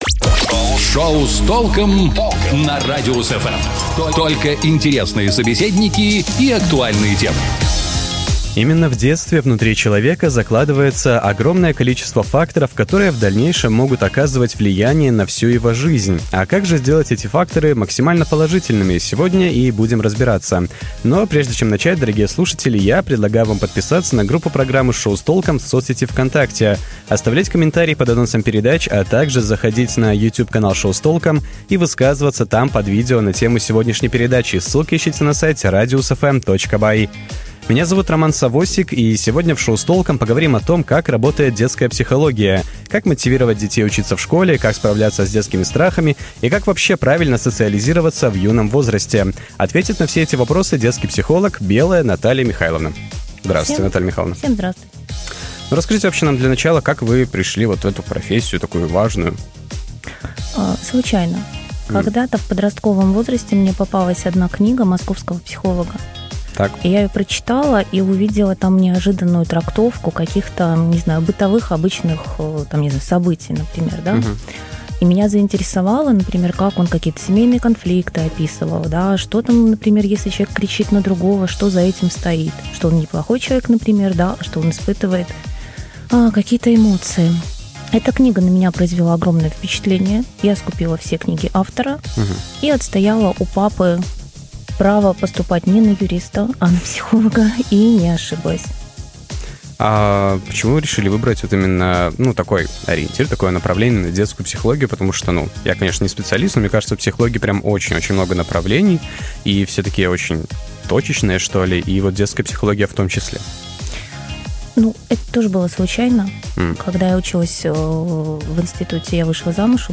Психолог о том, как детям правильно социализироваться и пережить буллинг